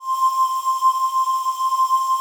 PAD 48-4.wav